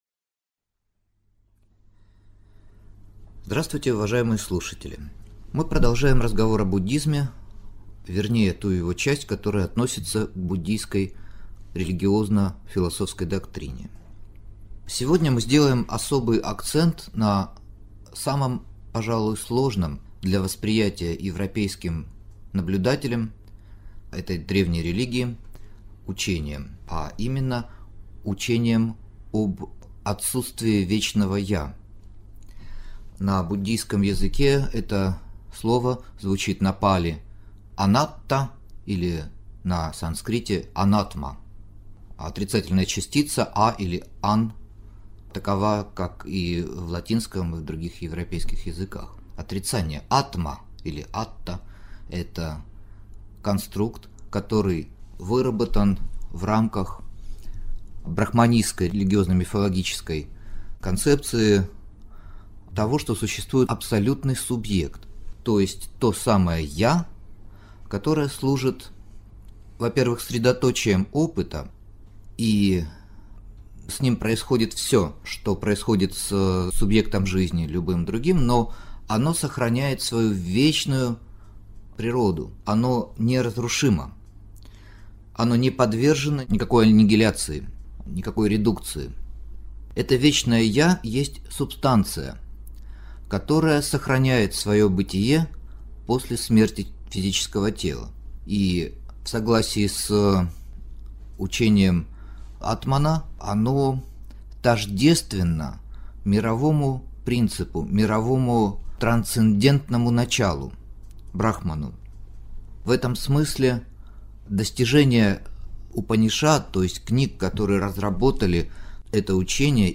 Аудиокнига Анатма – учение о «не-я» | Библиотека аудиокниг